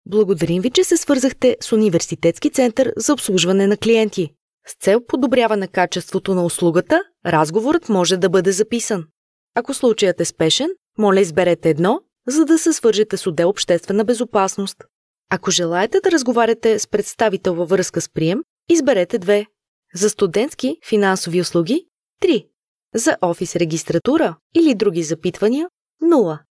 Listen to female Bulgarian Voice Artist